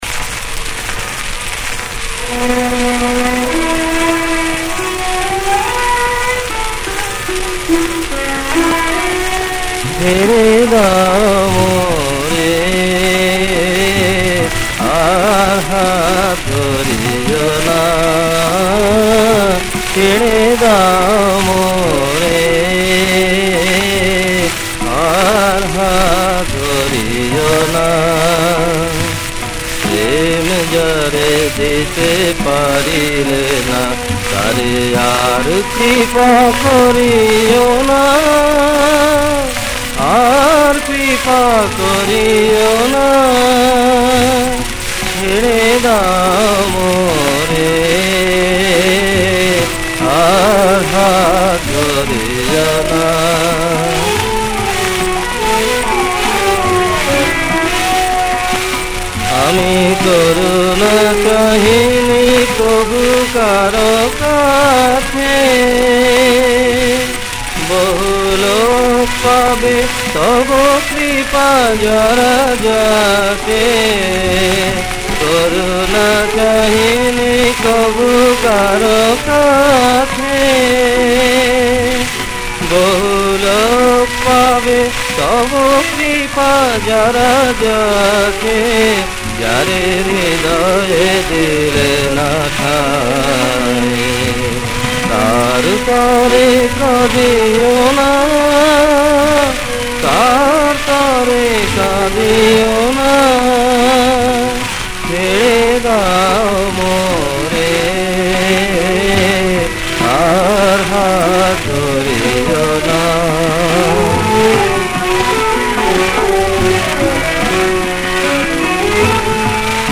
• সুরাঙ্গ: খেয়ালাঙ্গ
• রাগ: হর্ষ কানাড়া।
• ত্রিতাল।